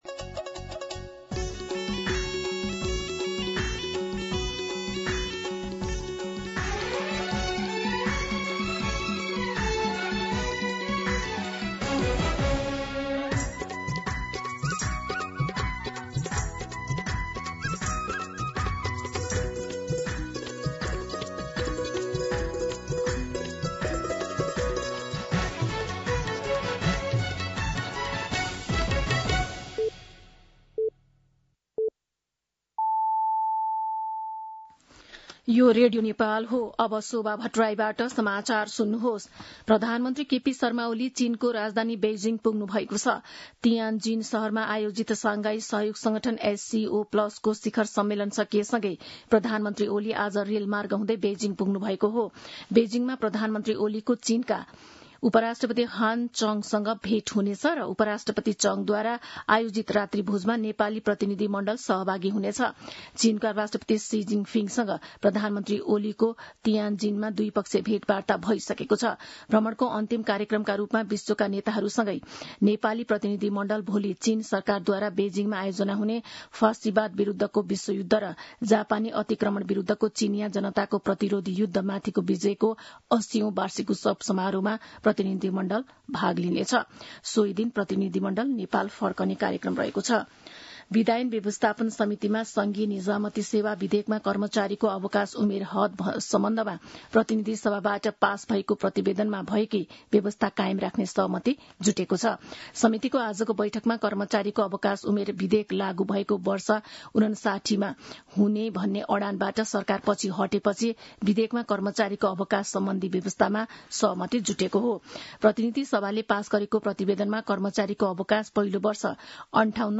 दिउँसो १ बजेको नेपाली समाचार : १७ भदौ , २०८२
1pm-News.mp3